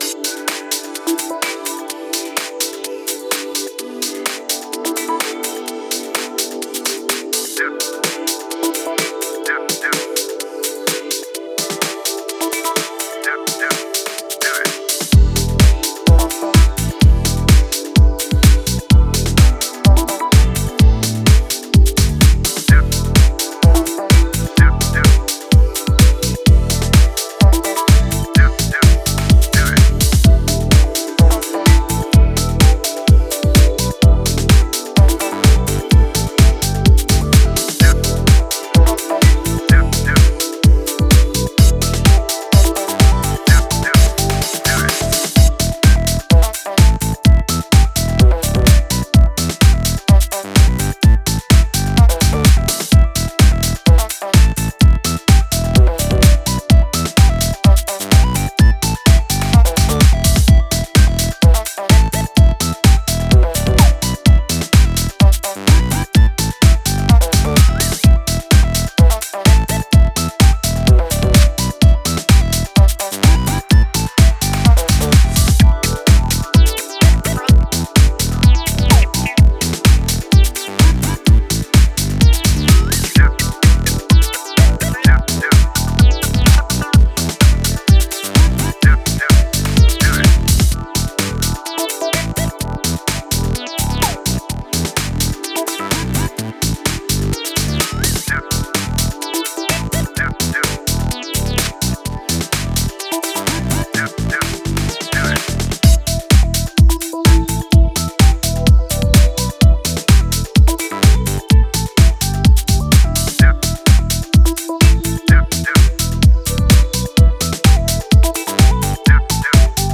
コミカルかつメロディアスにバウンスする